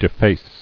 [de·face]